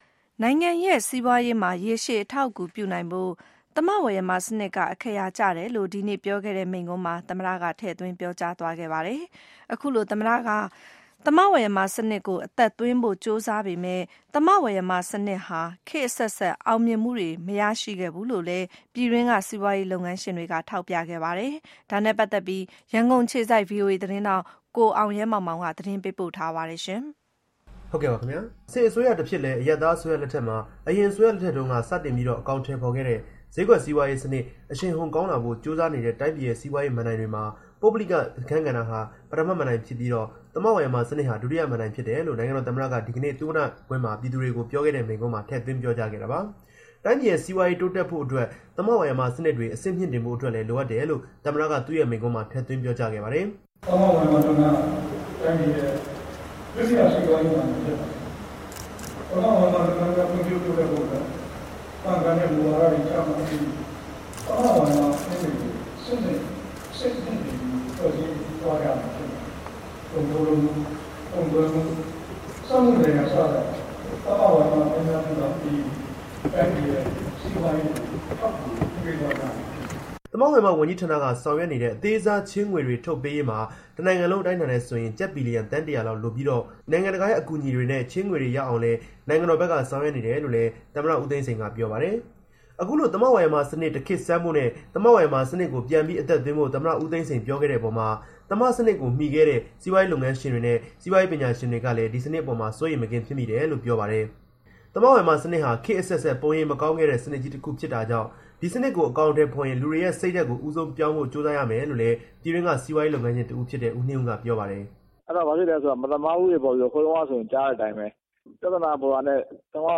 U Thein Sein speech